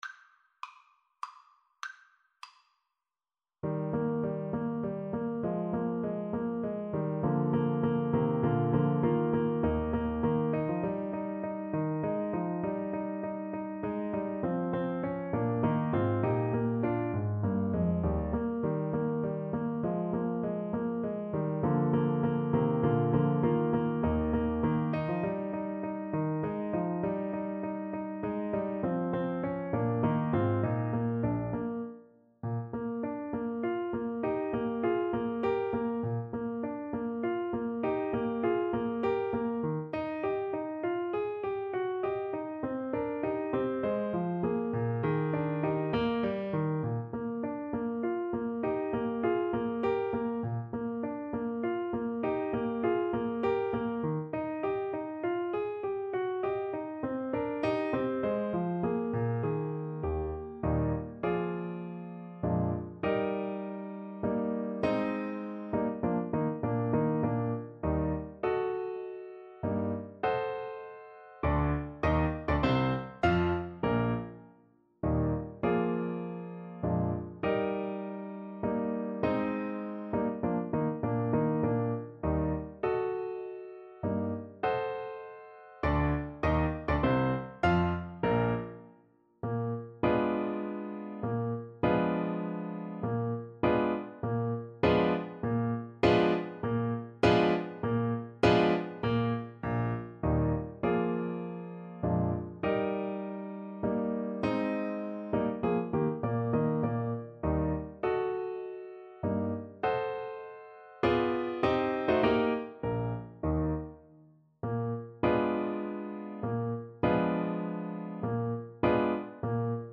3/4 (View more 3/4 Music)
Menuetto Moderato e grazioso
Classical (View more Classical Saxophone Music)